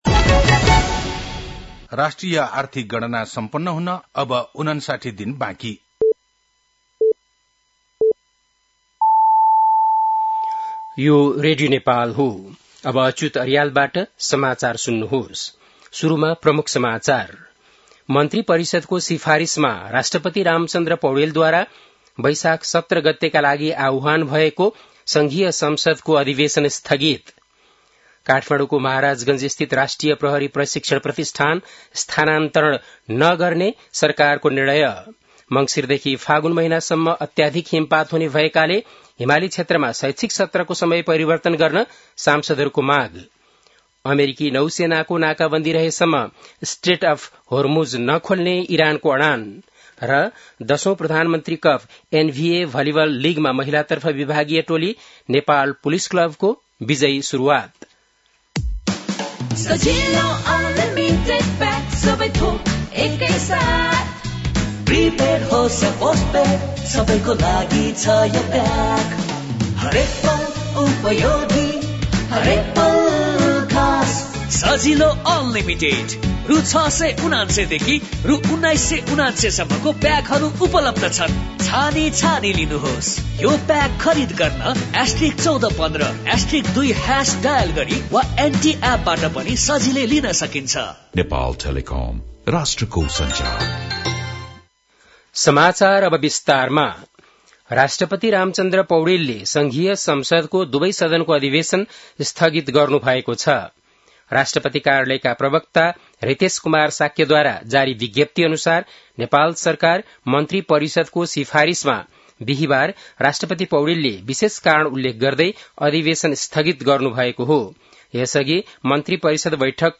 बेलुकी ७ बजेको नेपाली समाचार : १० वैशाख , २०८३
7-pm-nepali-news-1-10.mp3